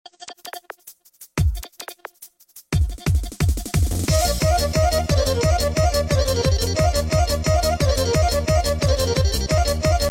Buttons Sound Effects MP3 Download Free - Quick Sounds